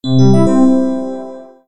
01_boot.wav